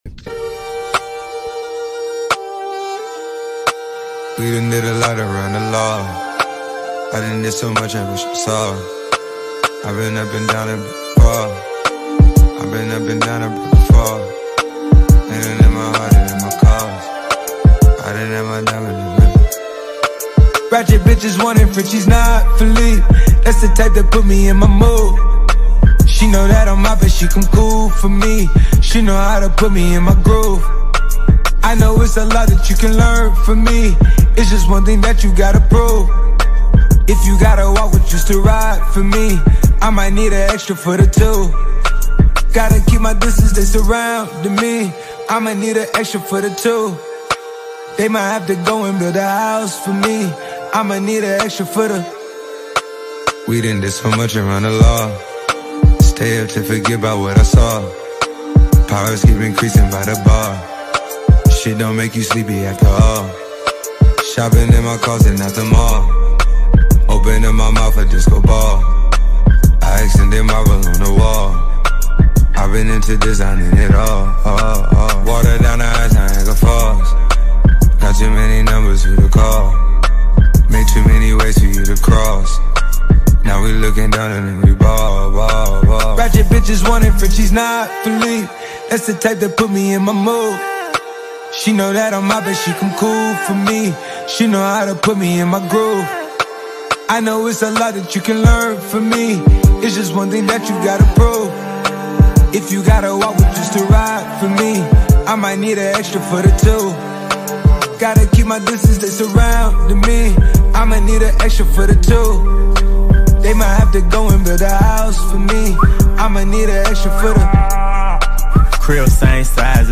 это мощный трек в жанре хип-хоп